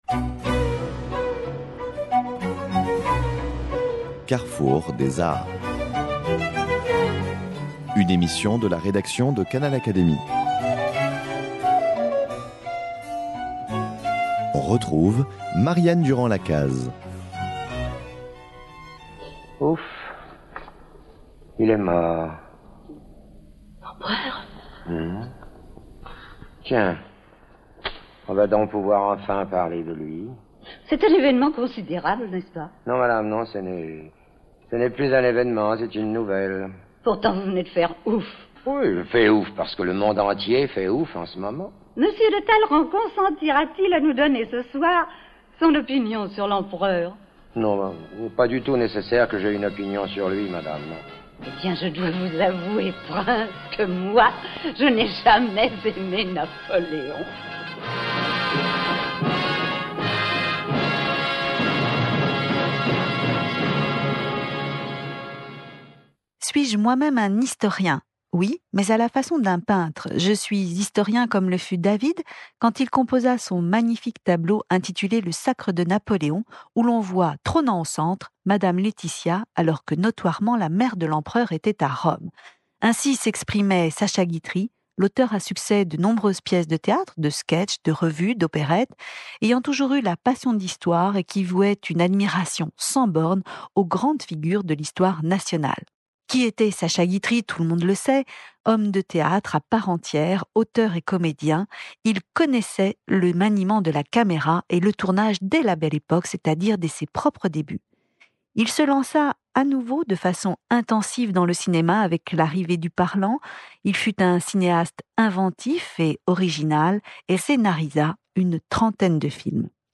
Jean Tulard, membre de l'Institut, historien bien connu de l'époque napoléonienne et de l'histoire du cinéma, évoque pour nous, dans cet entretien, le talent, le sens de la formule et la fantaisie de Sacha Guitry mais surtout sa volonté de s'exprimer sur l'histoire politique de sa propre époque sous le couvert de quelques grands personnages historiques. Il retrace l'accueil de ses films par les historiens et situe les films de Sacha Guitry dans leur contexte historique et politique.